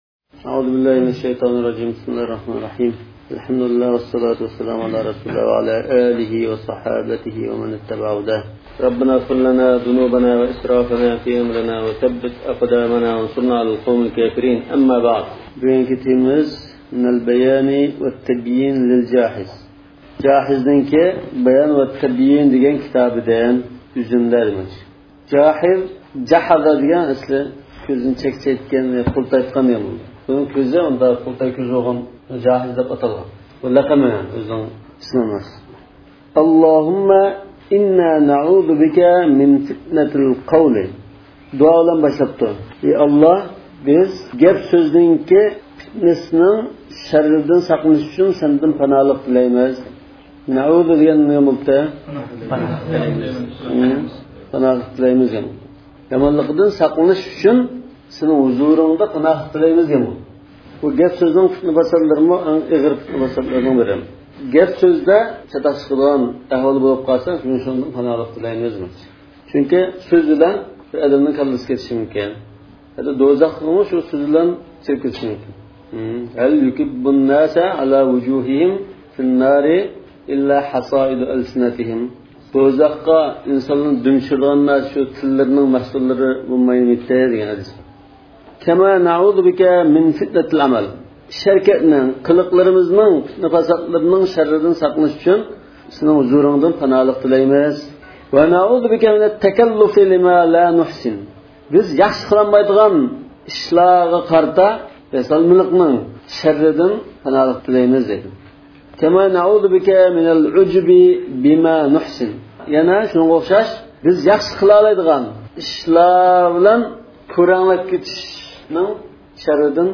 المطالعة الأزهرية 1. قىسىم 38 – دەرس. جاھىزنىڭ » ئەلبايان ۋەتەبييىن» ناملىق كىتابىدىن ئۈزۈندىلەر